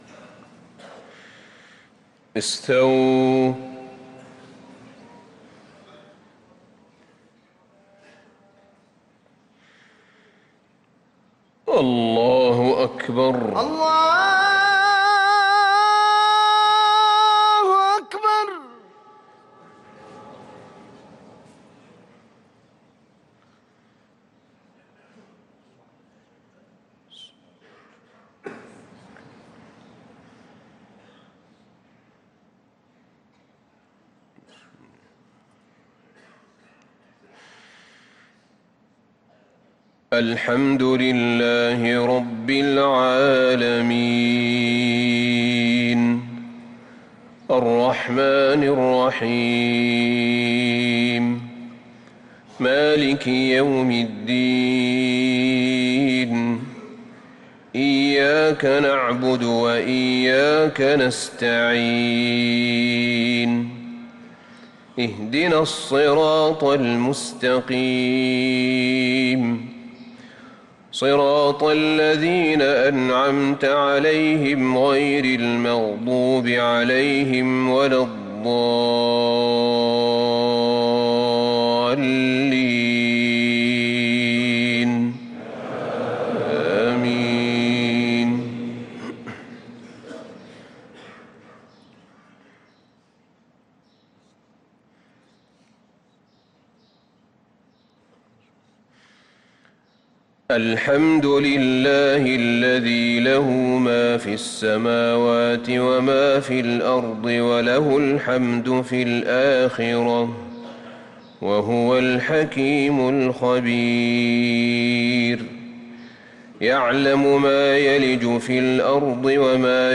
صلاة الفجر للقارئ أحمد بن طالب حميد 12 جمادي الأول 1445 هـ
تِلَاوَات الْحَرَمَيْن .